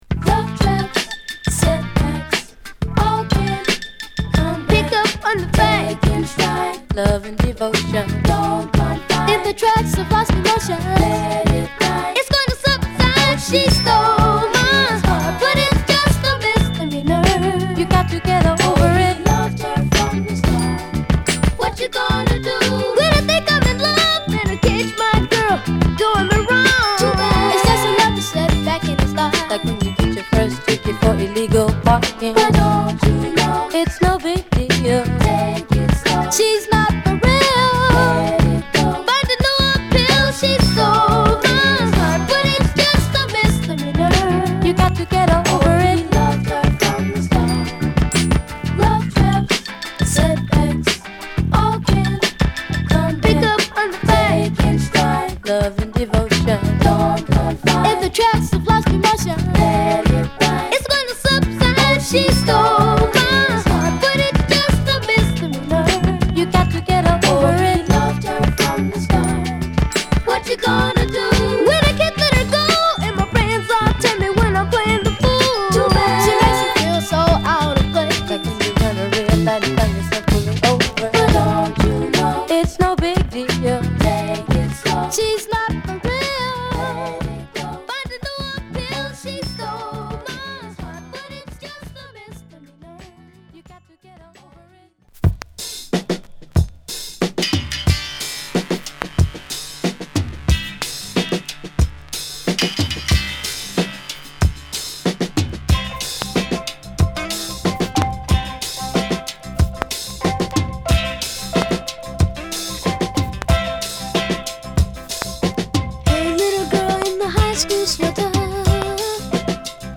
＊B1に大きな傷有り。